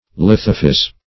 Search Result for " lithophyse" : The Collaborative International Dictionary of English v.0.48: Lithophyse \Lith"o*physe\, n. [Litho- + Gr.